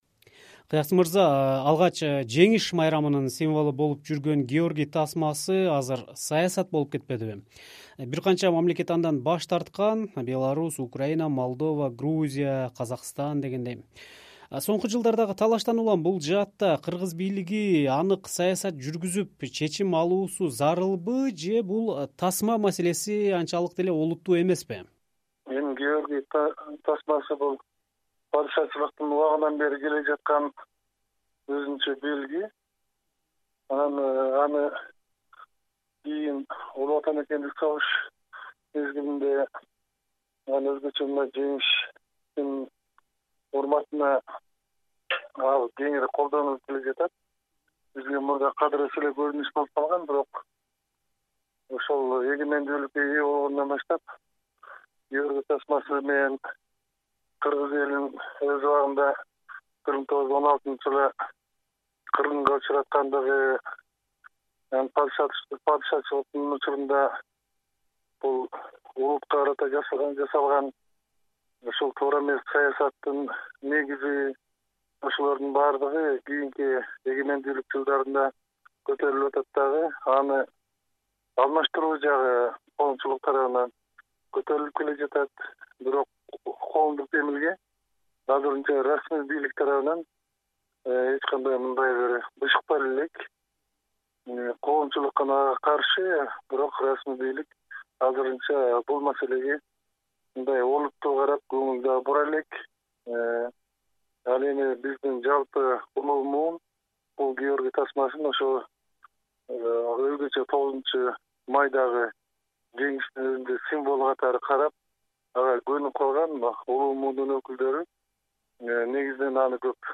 анын кыргыз-орус саясатындагы мааниси жөнүндө "Азаттык" радиосуна маек курду.